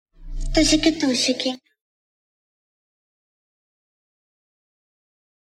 женский голос
короткие
цикличные